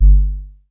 F_07_Bass_03_SP.wav